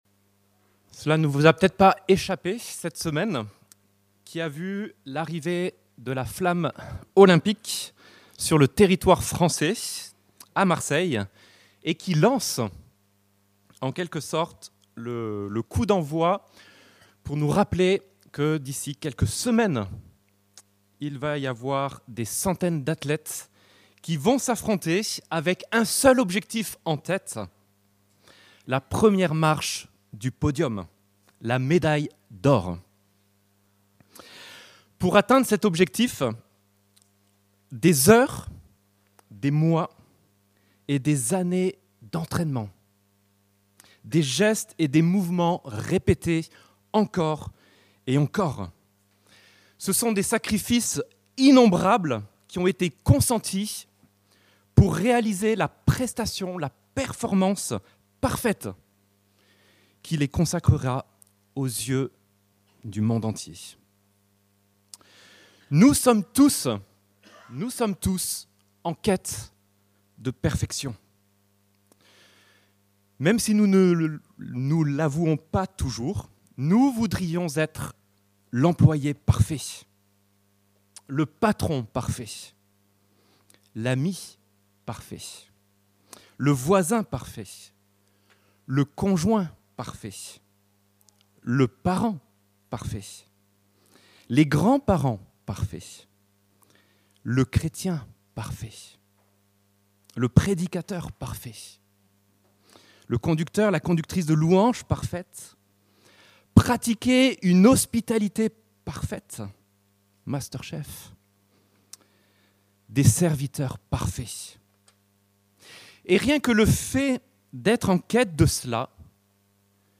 En quête de perfection - Prédication de l'Eglise Protestante Evangélique de Crest sur le livre des Hébreux